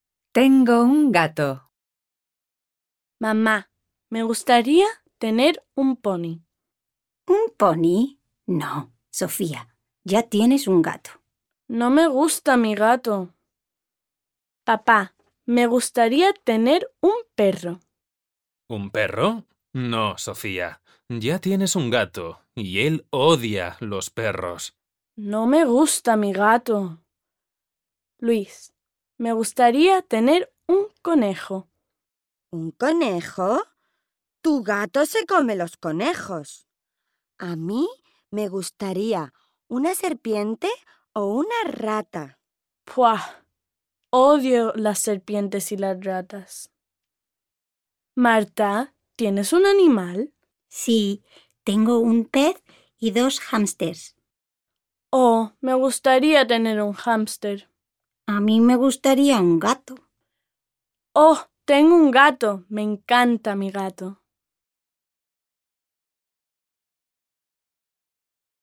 Listen to native speakers performing the story 'Tengo un gato'